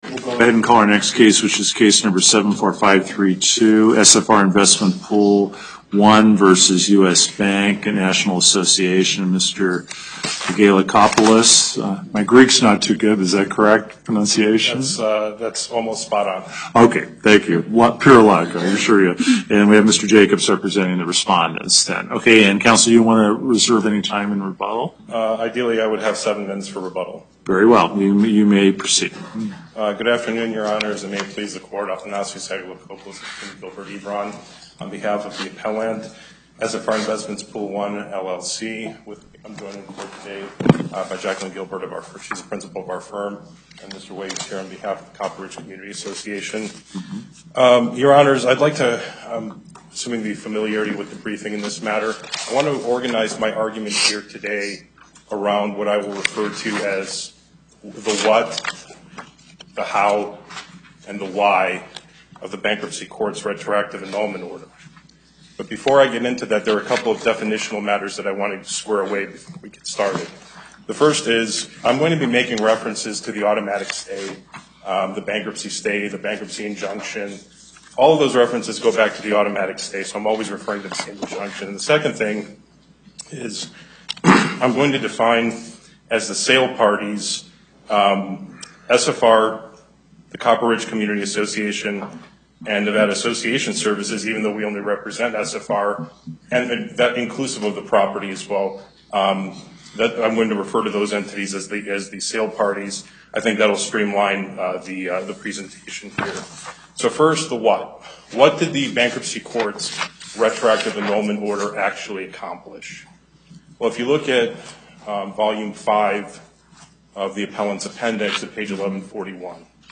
1:30 PM Location: Carson City Before the En Banc court, Chief Justice Gibbons Presiding Appearances